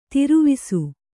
♪ tiruvisu